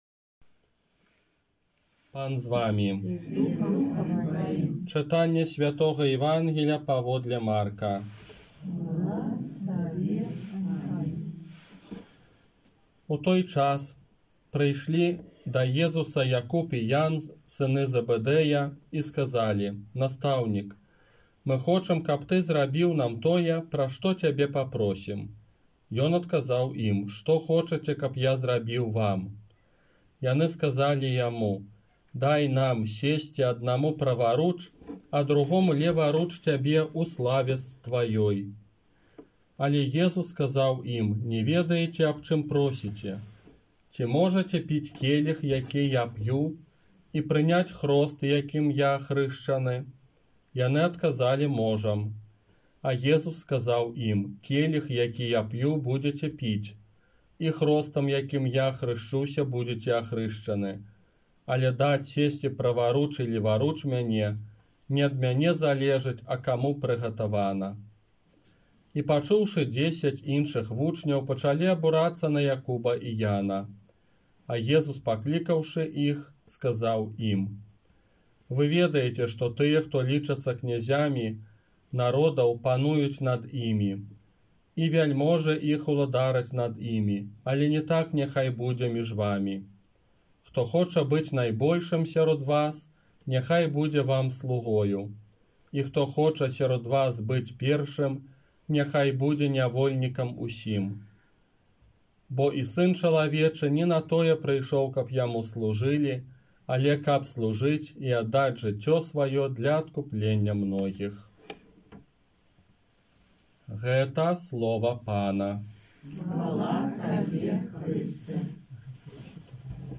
ОРША - ПАРАФІЯ СВЯТОГА ЯЗЭПА
Казанне на дваццаць дзевятую звычайную нядзелю 17 кастрычніка 2021 года